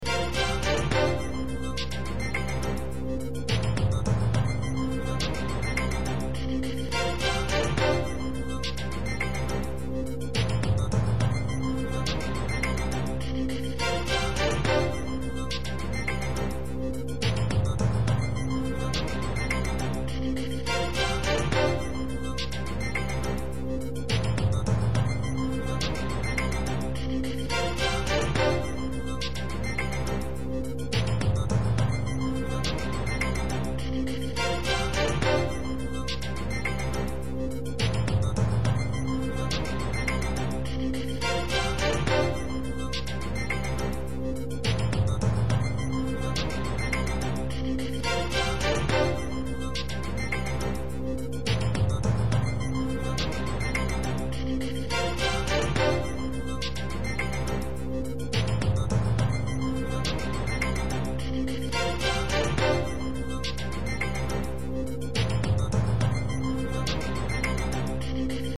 bed